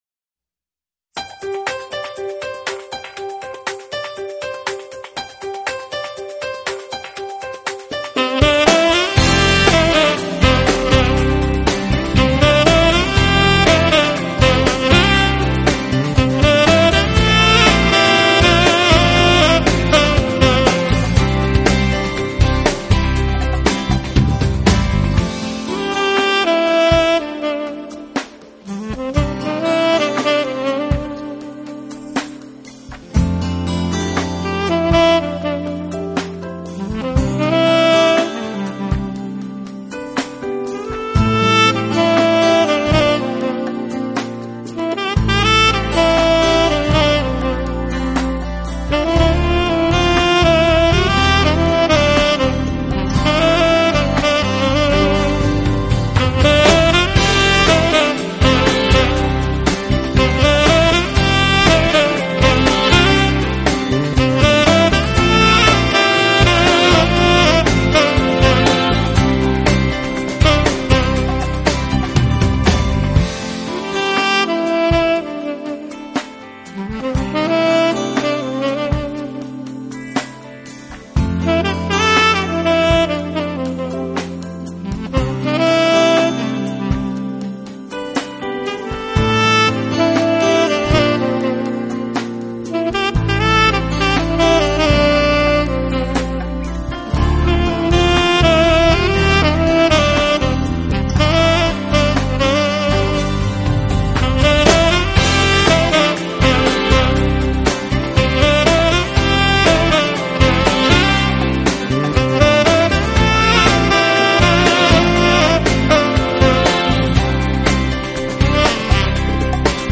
double bass
drums